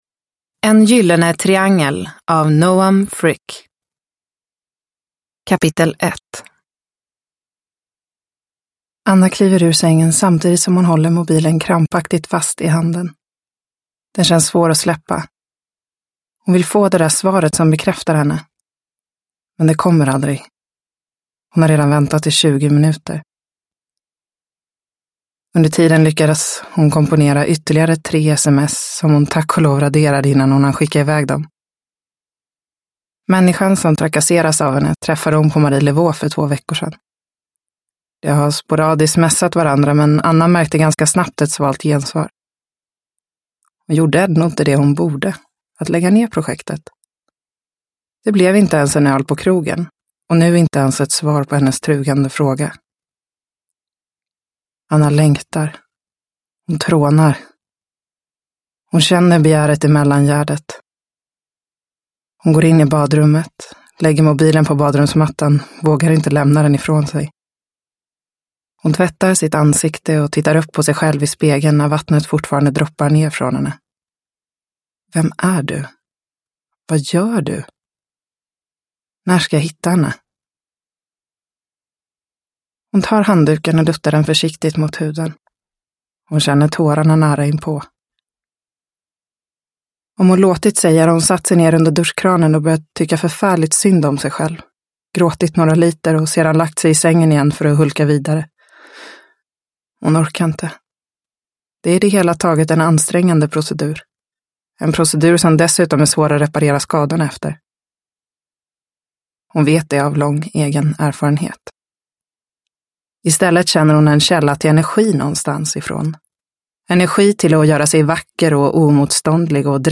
En gyllene triangel – Ljudbok